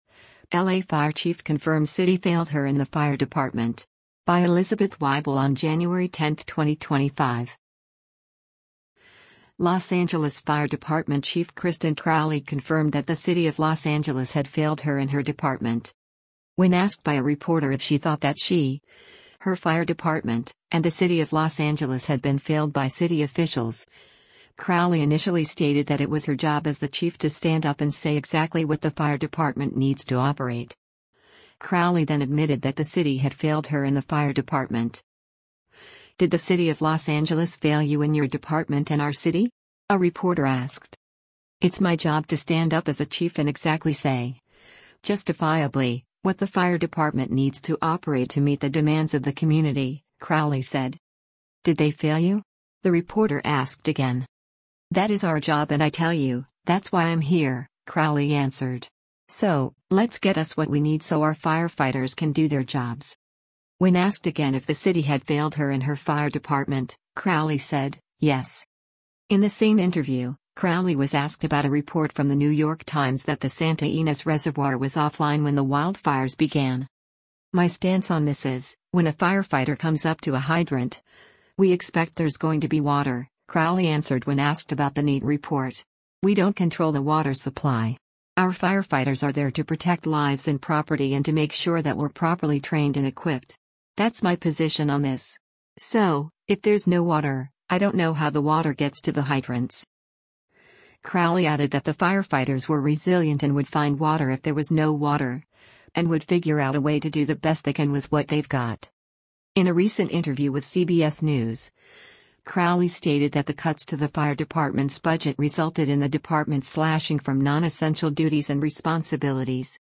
“Did the city of Los Angeles fail you and your department and our city?” a reporter asked.